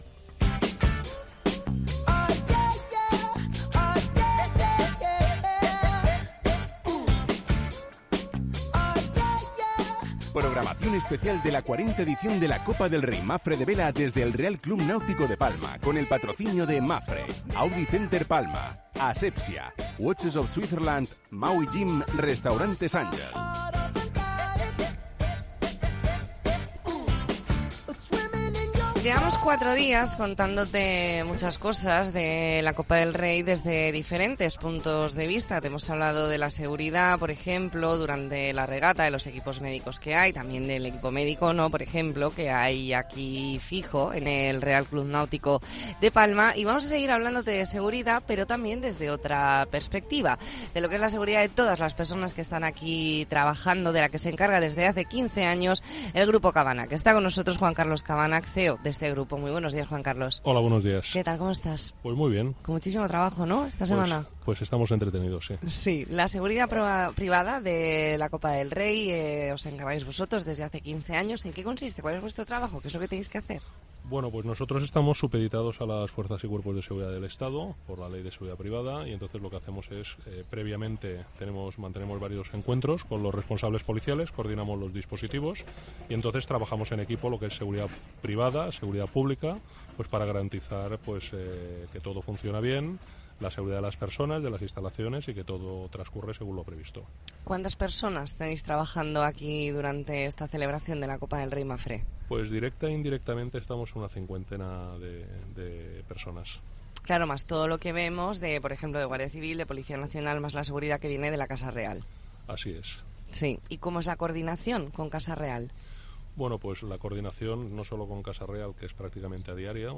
AUDIO: Especial La Mañana en COPE Más Mallorca desde el RCNP con motivo de la 40 Copa del Rey Mapfre